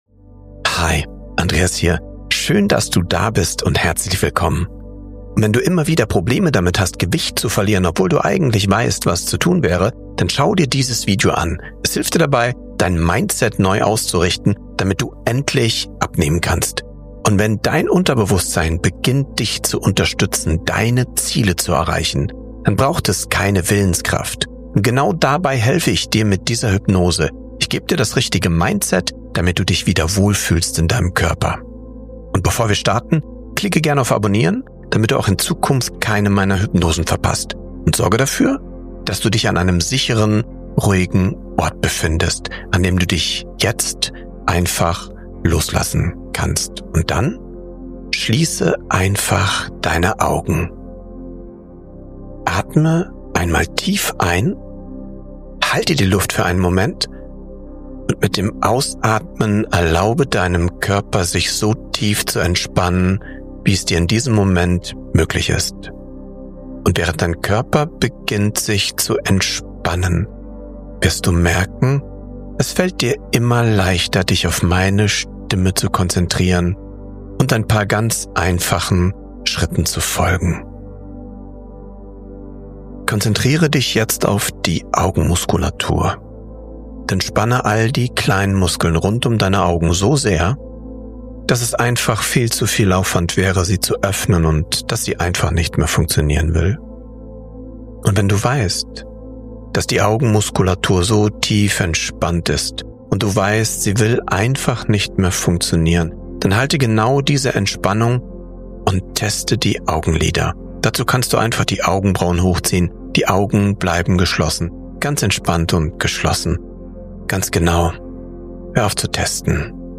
ABNEHM HYPNOSE | In 8 Min Dein Mindset neu programmieren (sehr stark)